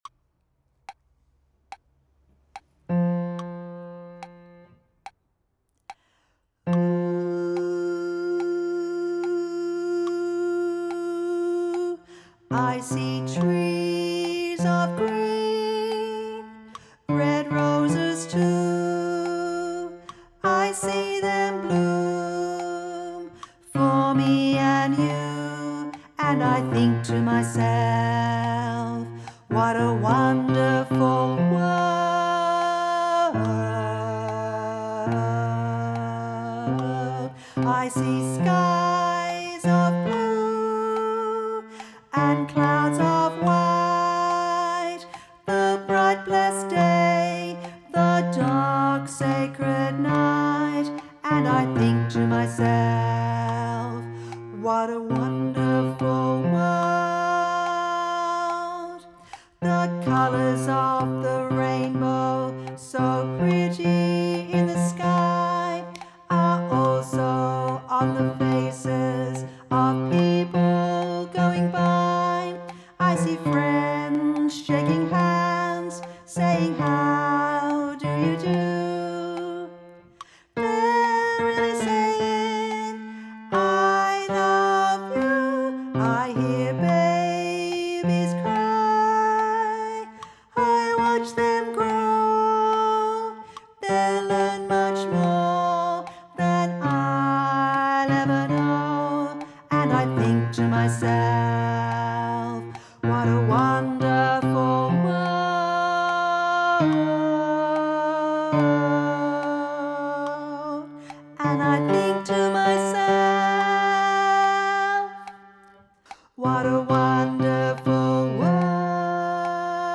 Vox Populi Choir is a community choir based in Carlton and open to all comers.
Arr. Fiona Evans Sheet Music Wonderful World Soprano Wonderful World Alto Wonderful World Bass Wonderful World All Parts
Wonderful_World-Bass.mp3